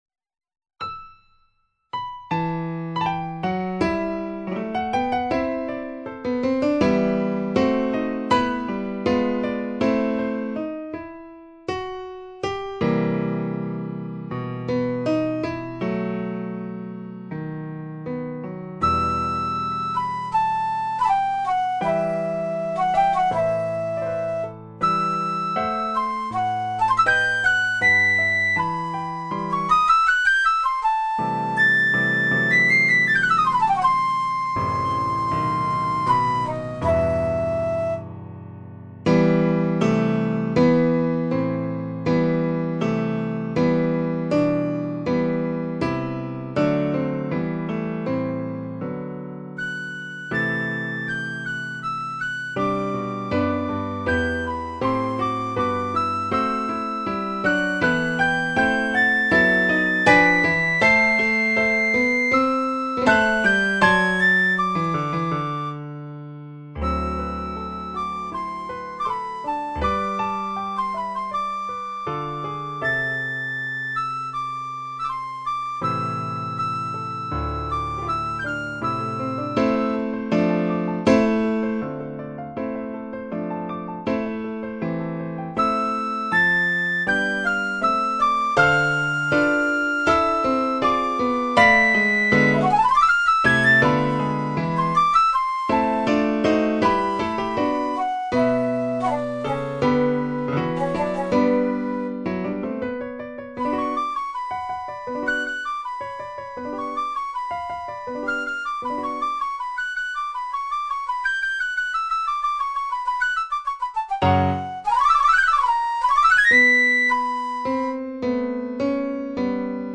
Instrumentalnoten für Flöte